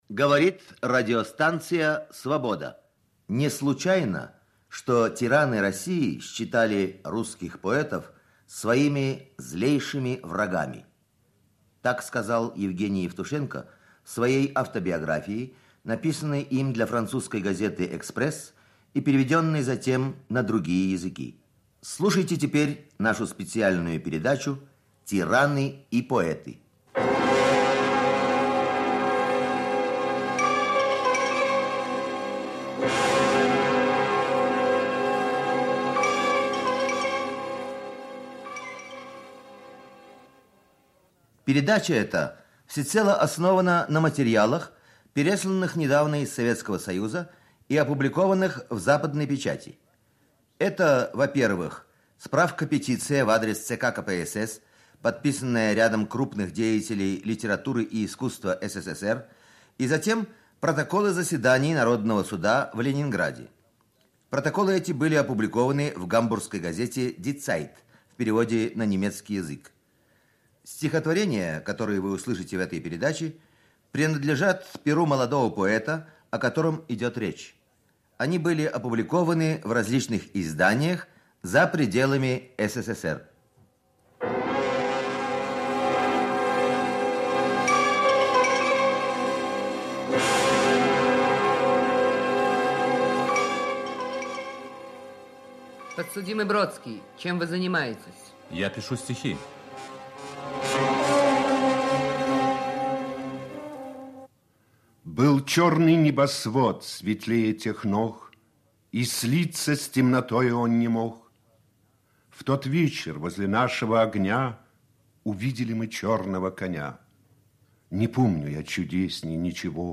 Панорама архивных передач с его участием на Радио Свобода